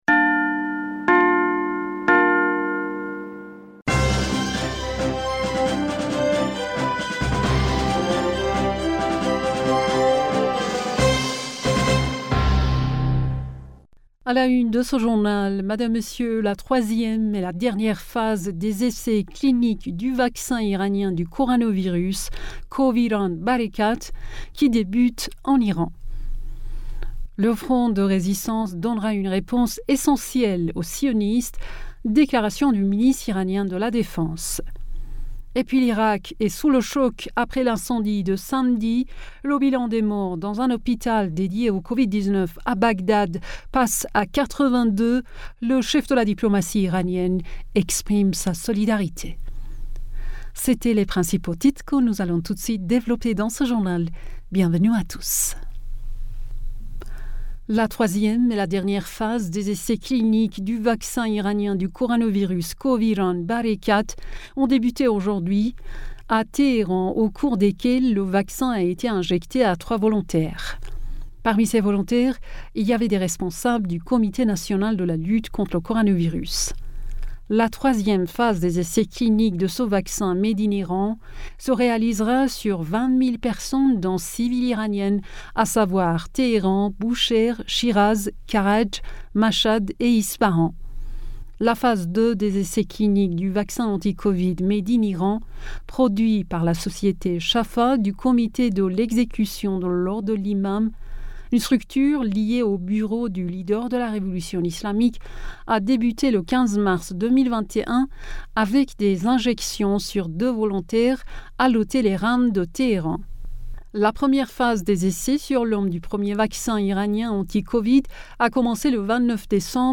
Bulletin d'information du 25 Avril 2021